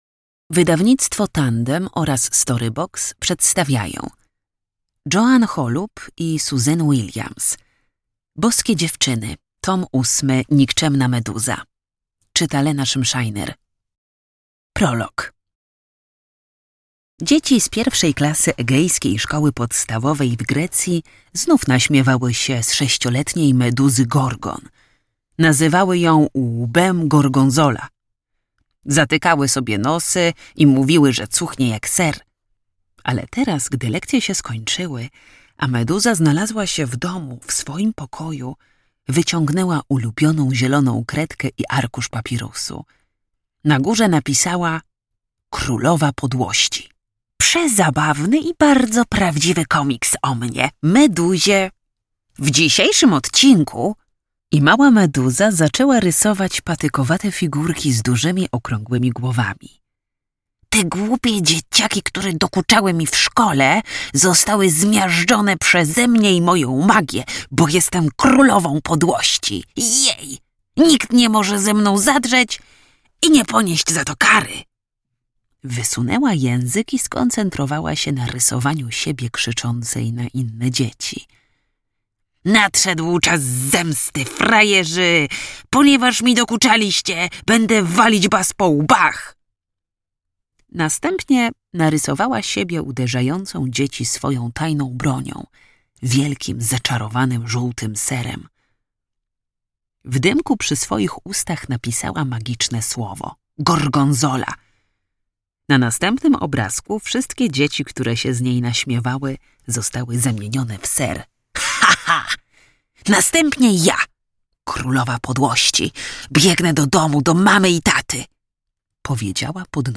Boskie dziewczyny. Tom 8. Nikczemna Meduza - Joan Holub, Suzanne Williams - audiobook